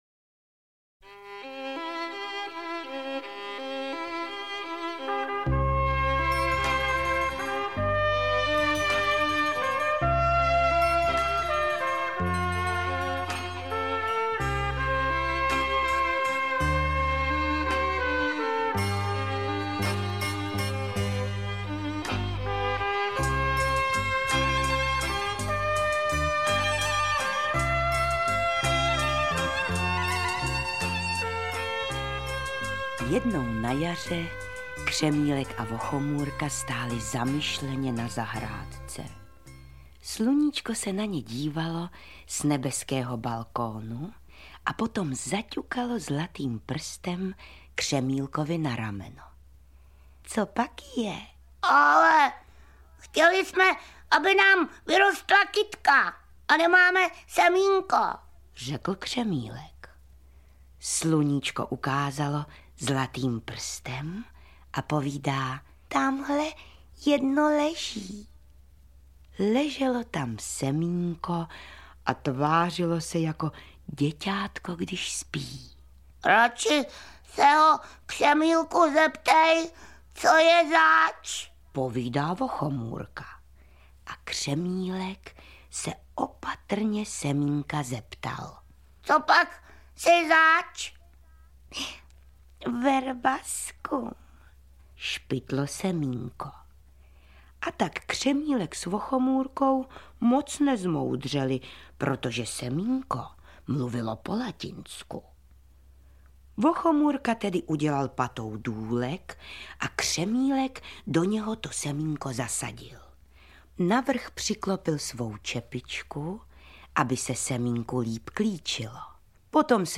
Audiobook
Read: Jiřina Bohdalová